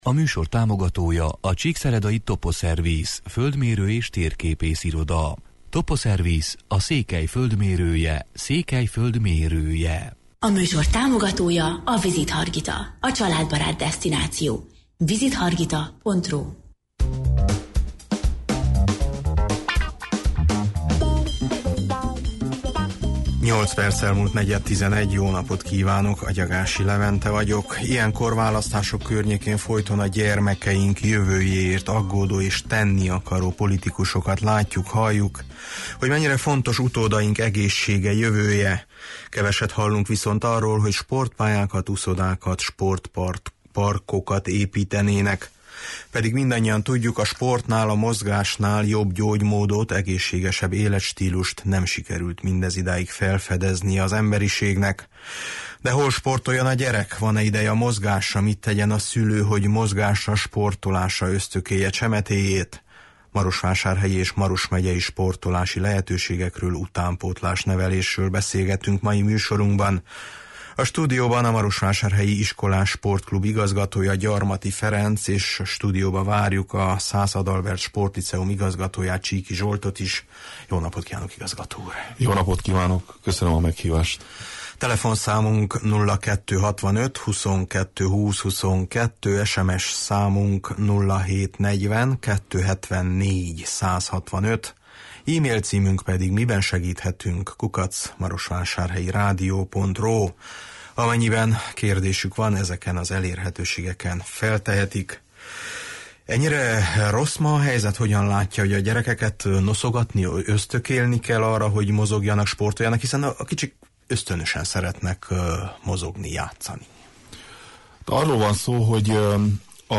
De hol sportoljon a gyerek, van-e ideje a mozgásra, mit tegyen a szülő, hogy mozgásra, sportolásra ösztökélje csemetéjét? Marosvásárhelyi és Maros megyei sportolási lehetőségekről, utánpótlásnevelésről beszélgetünk mai műsorunkban.